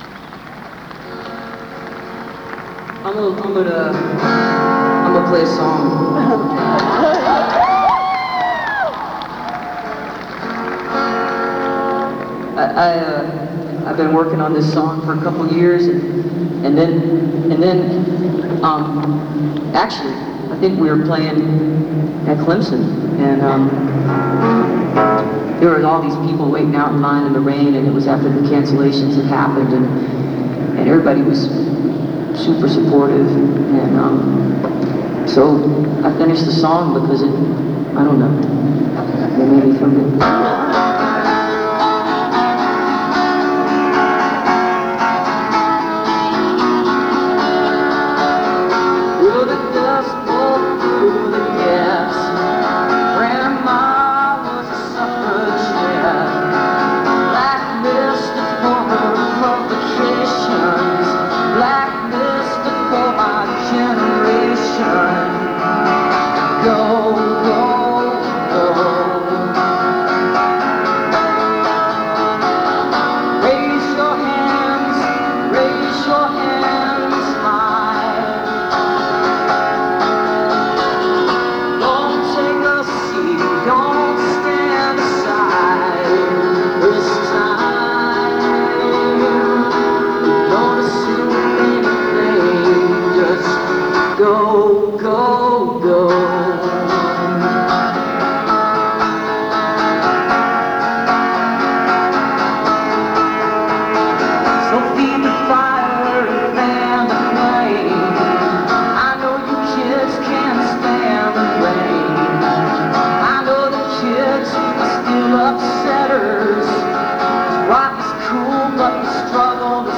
lifeblood: bootlegs: 1998-10-01: littlejohn coliseum - clemson university, south carolina (amy ray speaking on gay rights - spitfire spoken word tour)